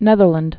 (nĕthər-lənd)